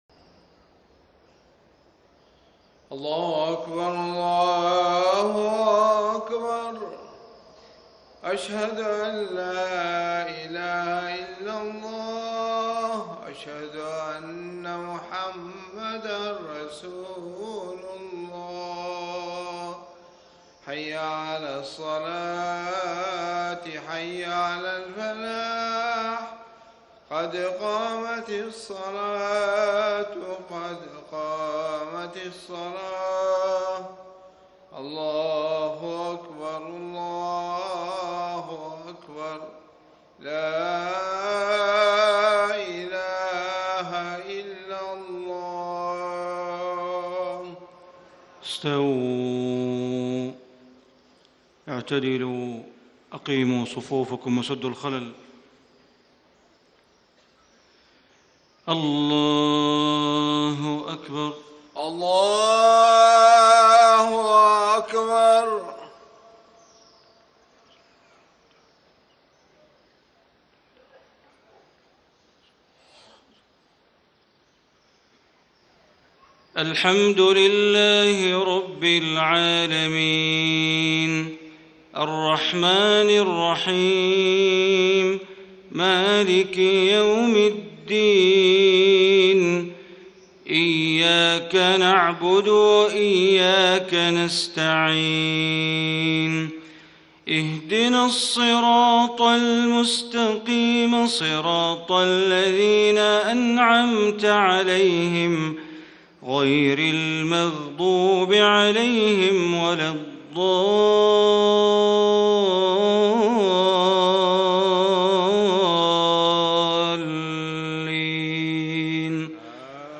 صلاة المغرب 6-6- 1435 ما تيسر من سورة يونس > 1435 🕋 > الفروض - تلاوات الحرمين